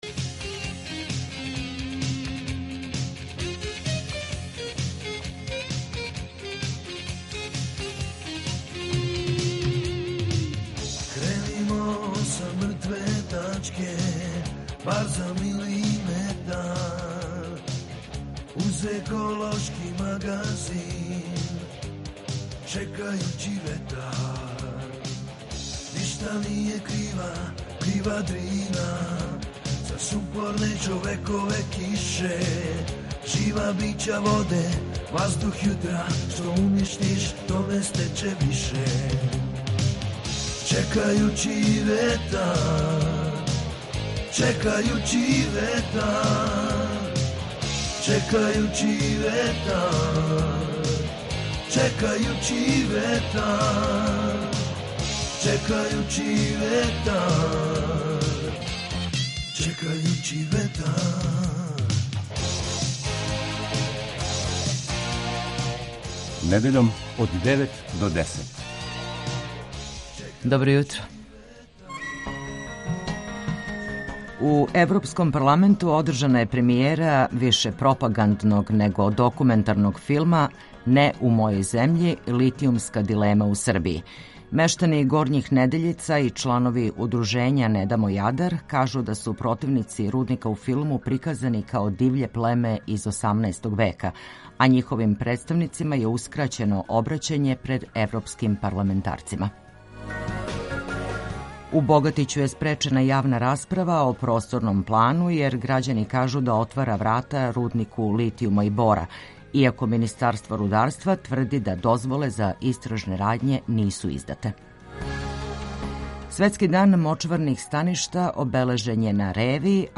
ekološki magazin Radio Beograda 2 koji se bavi odnosom čoveka i životne sredine